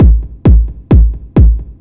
DS 132-BPM A2.wav